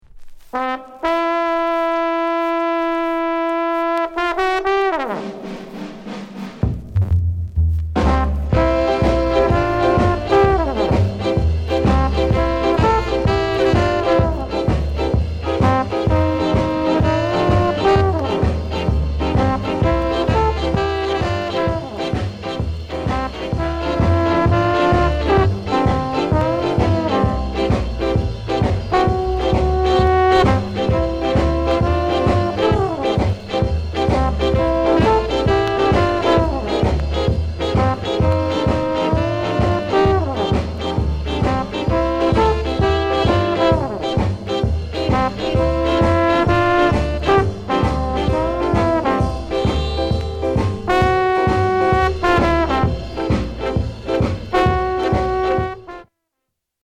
SKA INST